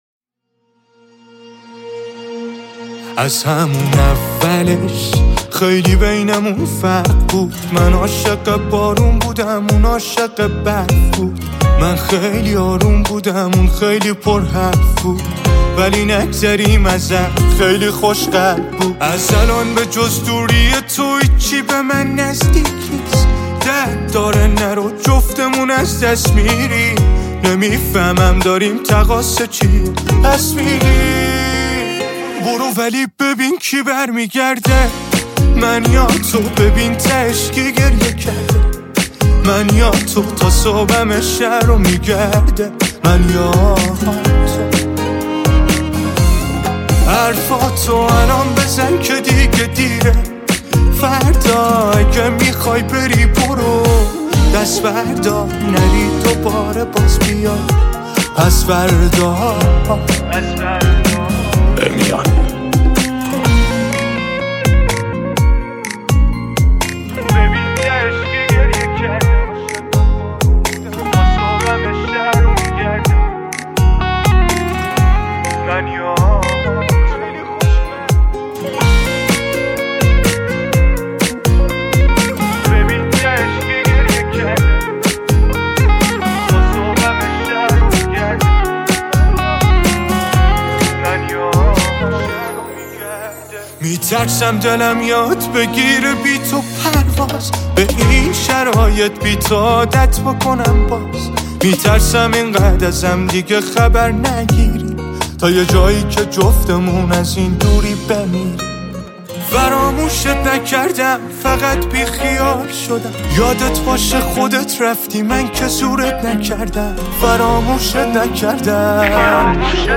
غمگین و احساسی
Sad Music